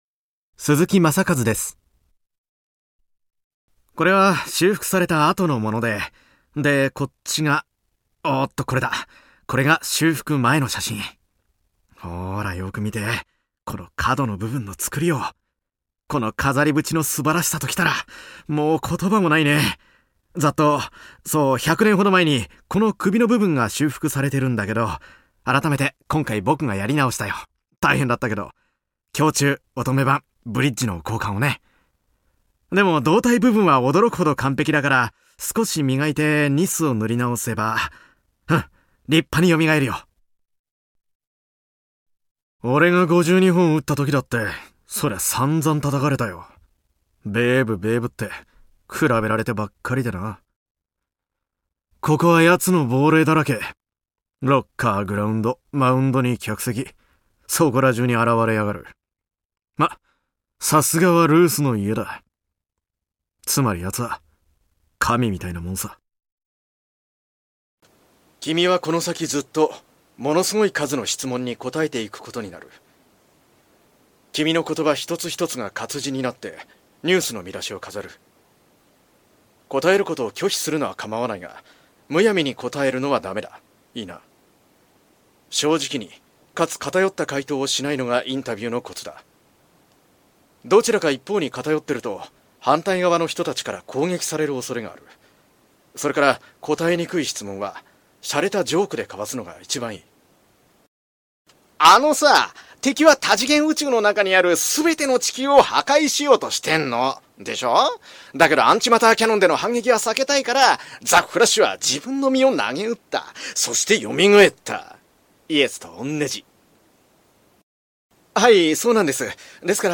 男性ナレーター 一覧に戻る